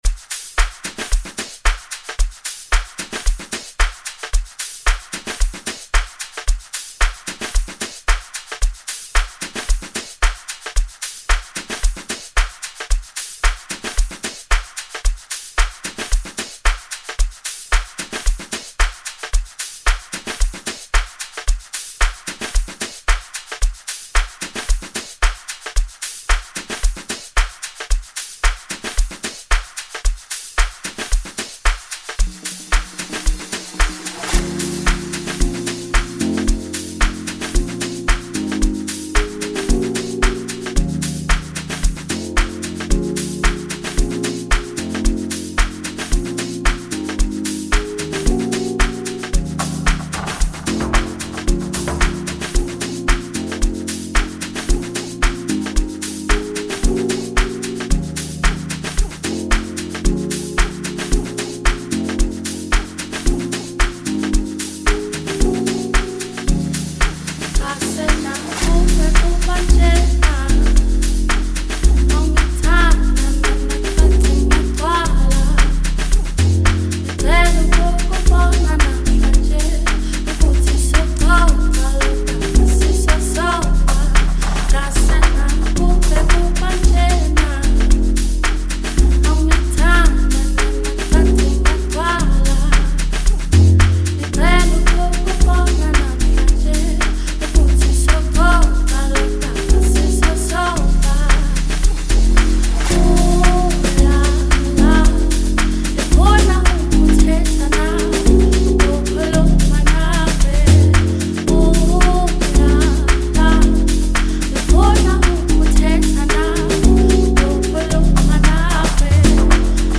it is still very sweet and very infectious
Afro beat